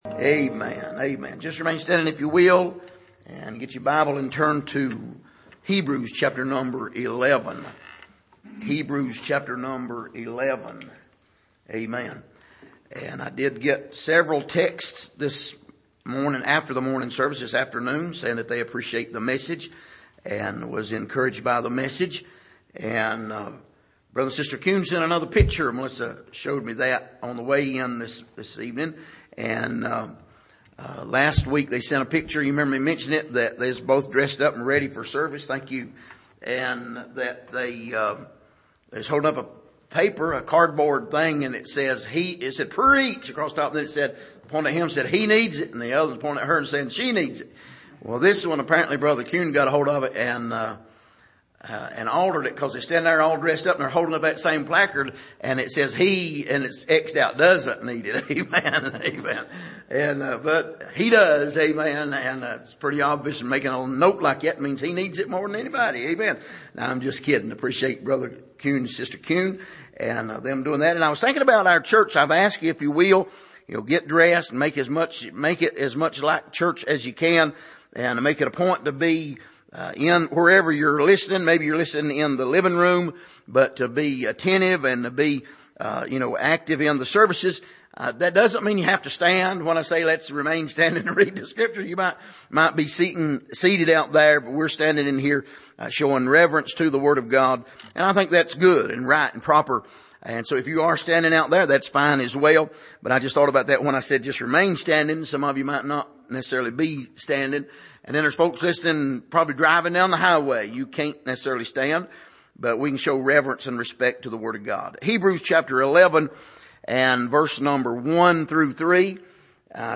Hebrews 11:1-3 Service: Sunday Evening What is Biblical Faith?